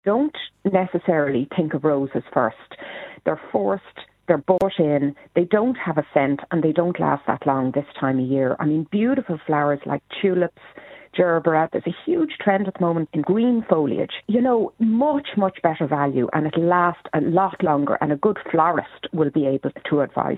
consumer columnist